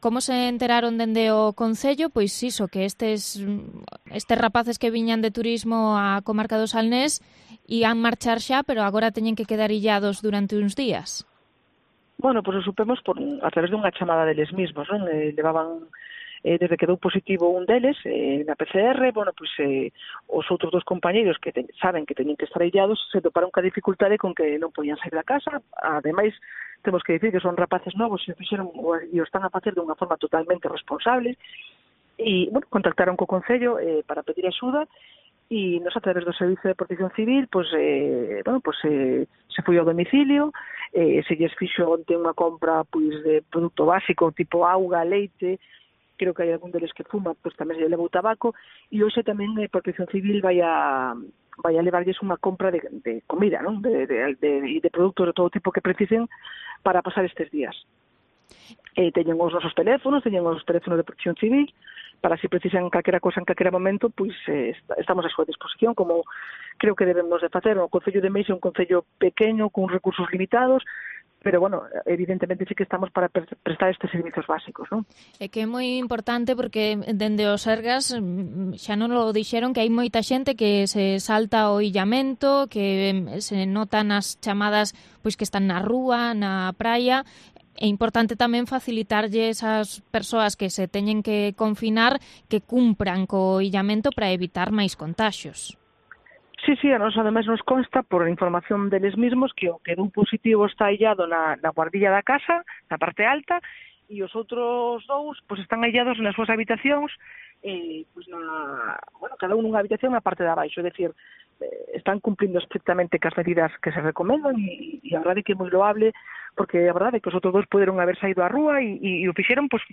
Entrevista a la alcaldesa de Meis sobre el positivo por covid-19 de un turista